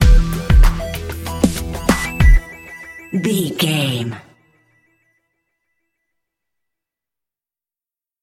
Aeolian/Minor
drums
electric piano
strings
soul
Funk
acid jazz
energetic
bouncy
funky